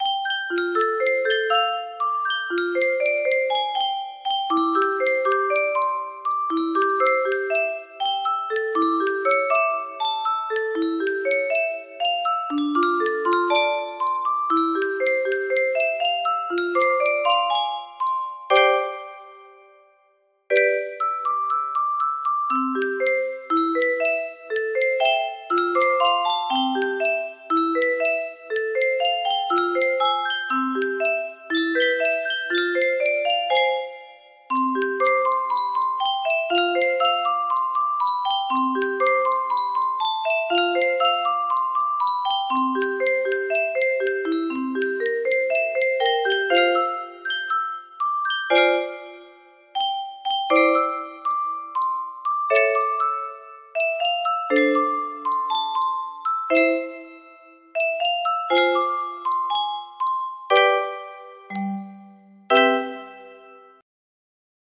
Alexandra music box melody
Grand Illusions 30 (F scale)